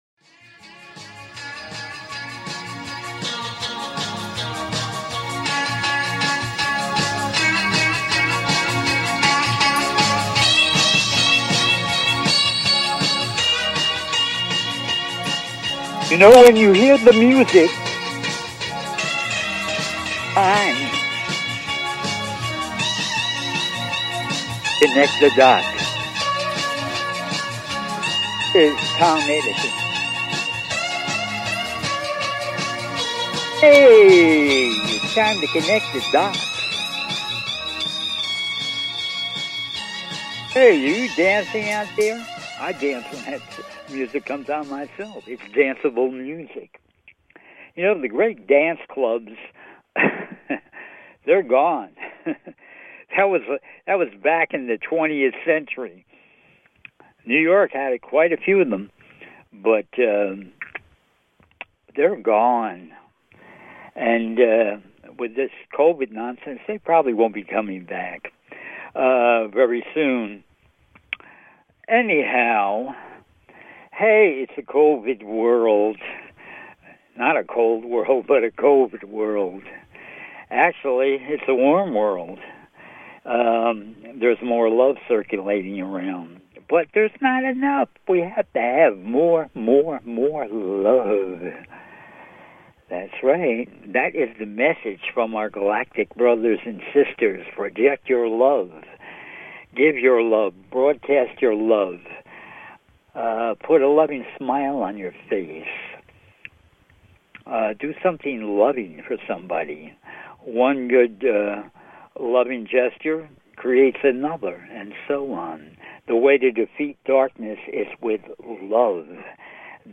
Talk Show Episode
"CONNECT THE DOTS" is a call in radio talk show